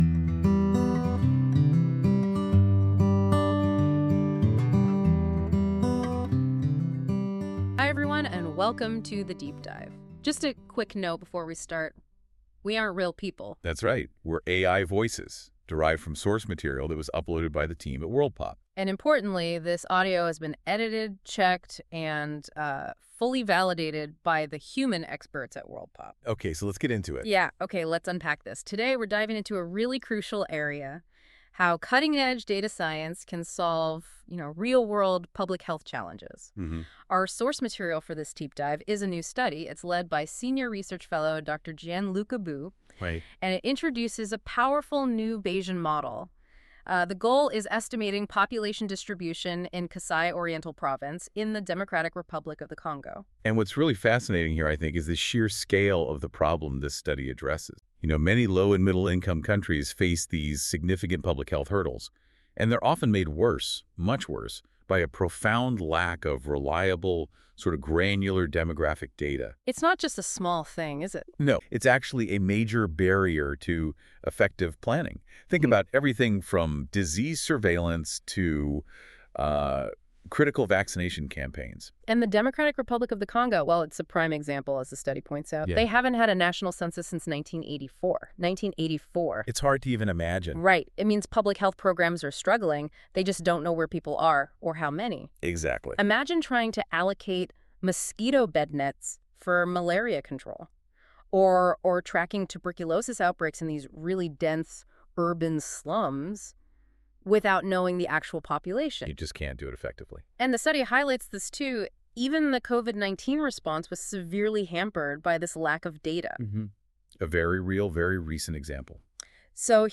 This feature uses AI to create a podcast-like audio conversation between two AI-derived hosts that summarise key points of a document - in this case the 'Tackling Public Health Data Gaps' article in PLOS Global Public Health.
Music: My Guitar, Lowtone Music, Free Music Archive (CC BY-NC-ND)